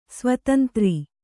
♪ svatantri